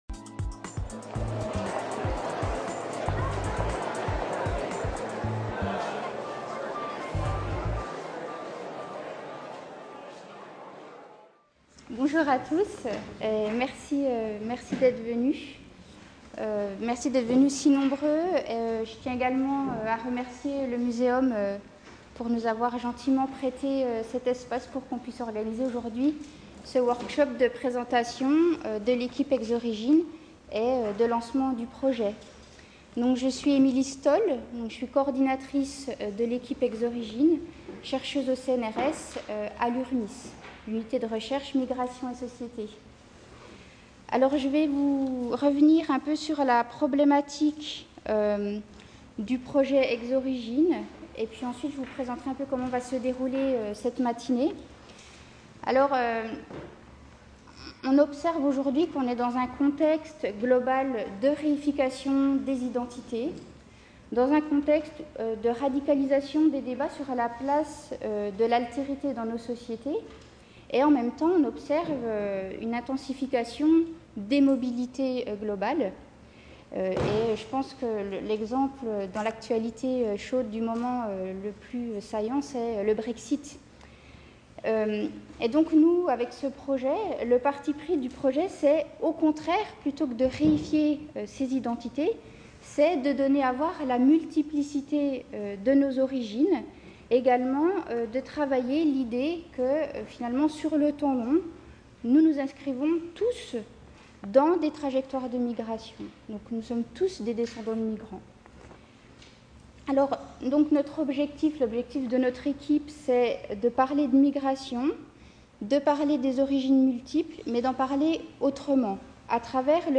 Jeudi 28 mars 2019, Jardin des Plantes, Paris INTRODUCTION DU WORKSHOP